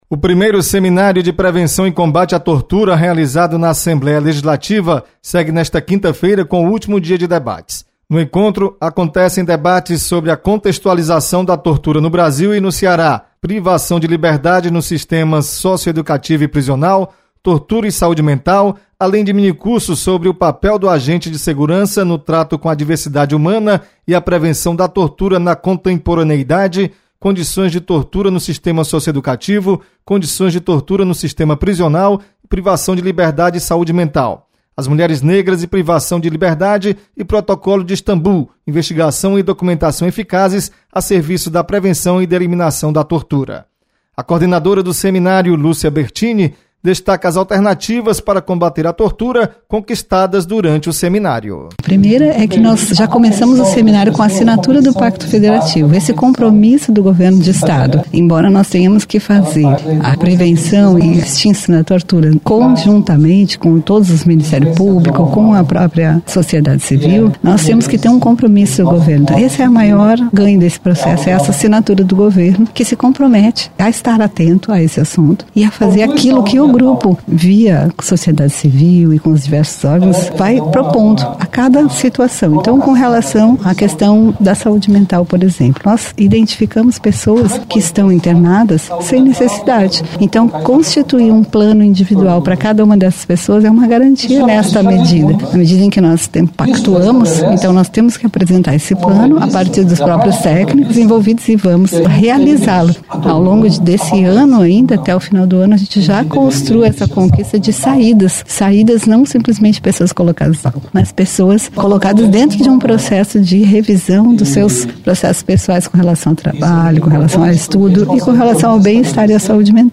Tortura e Saúde mental é um dos temas de debates do Primeiro Seminário de Prevenção e Combate a Tortura. Repórter